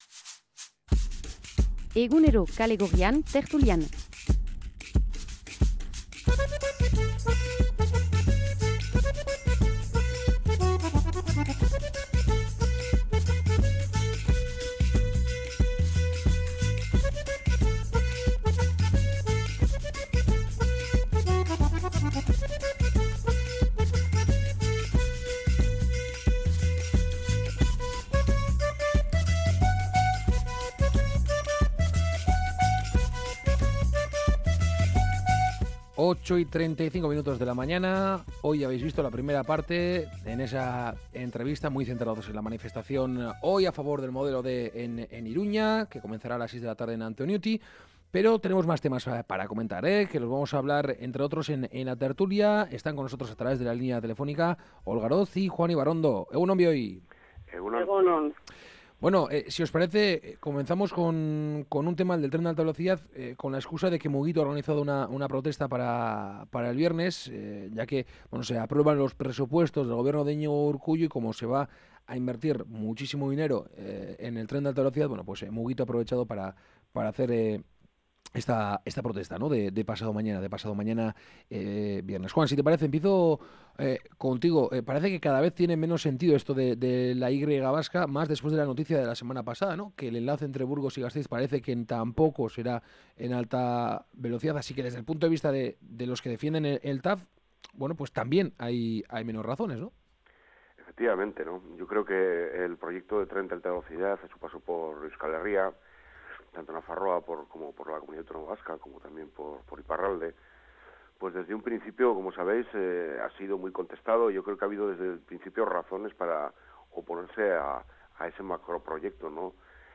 La tertulia de Kale Gorrian
Charlamos y debatimos sobre algunas de las noticias mas comentadas de la semana con nuestros colaboradores habituales.